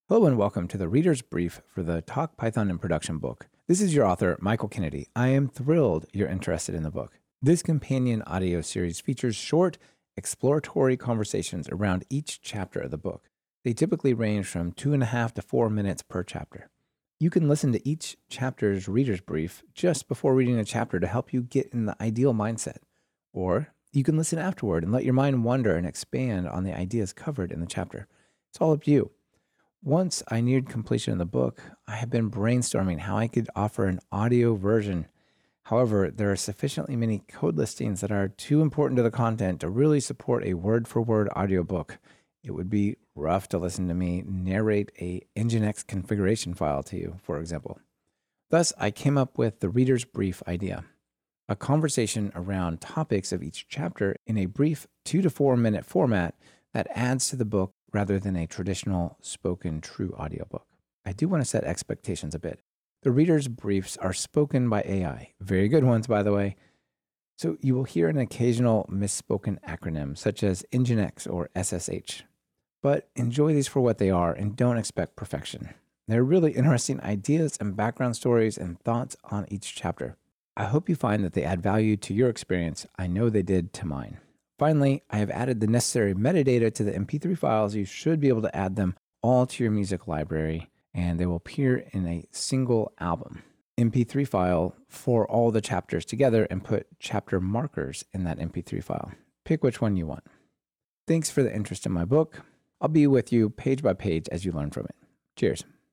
It clearly explains that this is an extra to the book, it's created with AI and meant to be fun.
00-readers-brief-intro.mp3